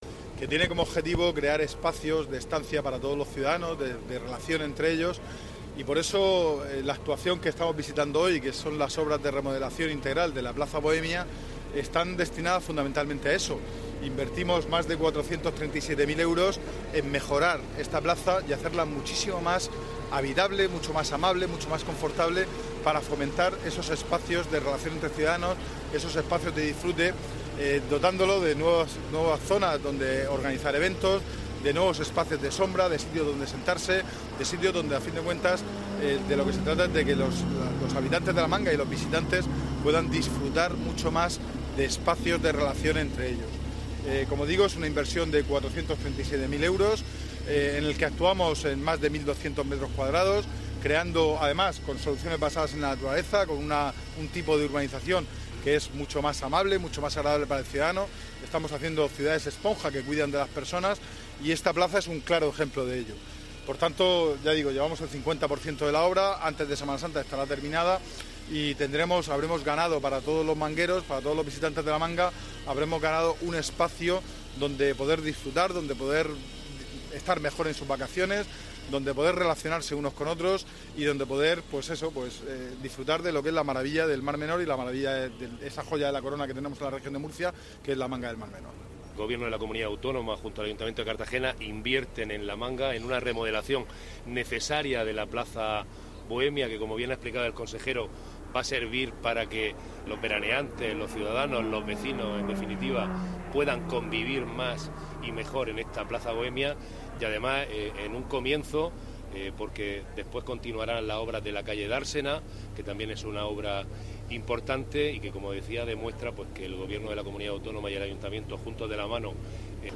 Enlace a Declaraciones sobre la obra en Plaza Bohemia